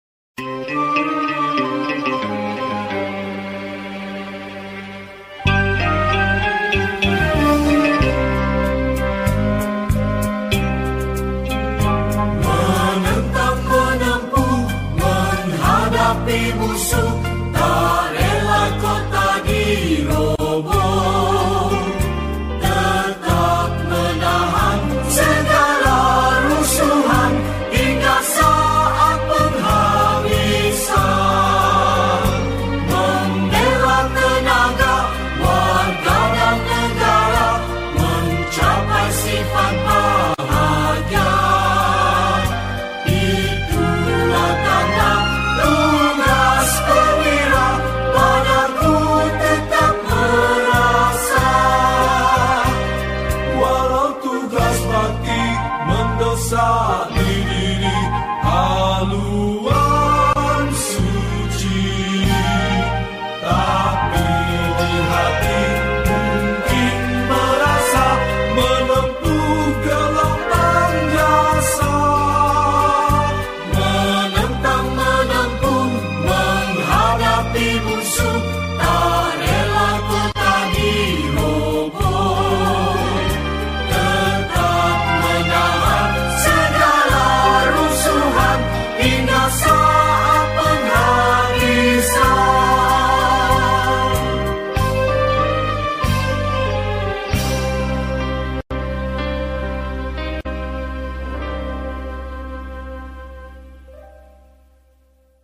Patriotic Songs
Lagu Patriotik Malaysia
Skor Angklung